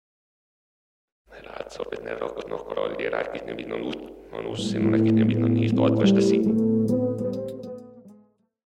Genere: reggae
Incomprensibile